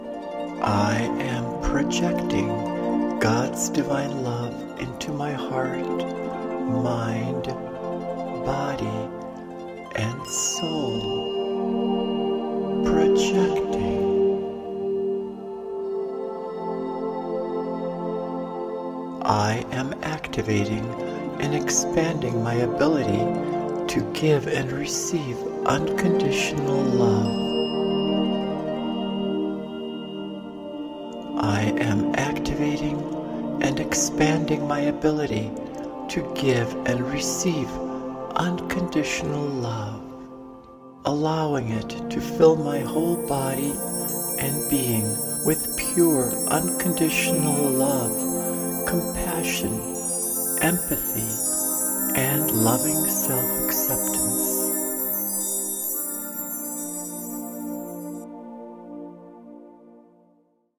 AN ADVANCED GUIDED MEDITATION